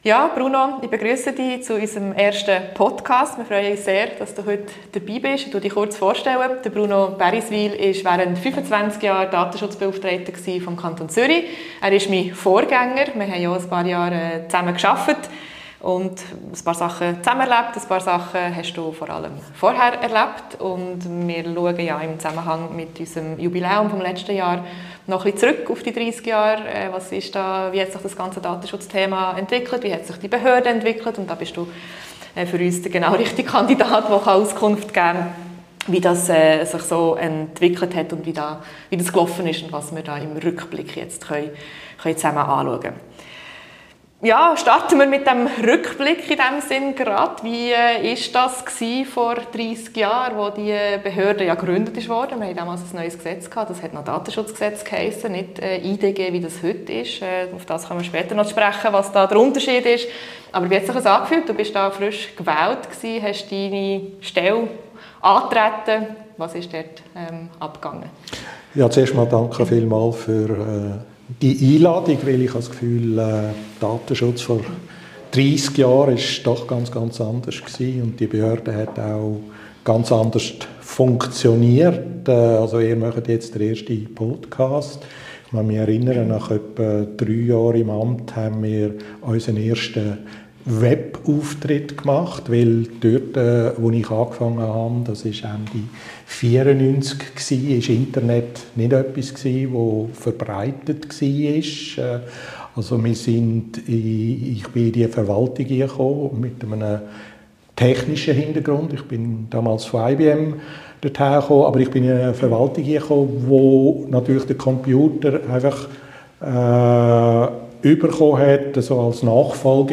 In der ersten Ausgabe des Podcasts diskutiert die Zürcher Datenschutzbeauftragte Dominika Blonski mit ihrem Vorgänger Bruno Baeriswyl über die ersten 30 Jahre der Zürcher Datenschutzgesetzgebung sowie die Anfänge der Datenschutzbehörde, die ebenfalls 1995 ihre Tätigkeit aufnahm.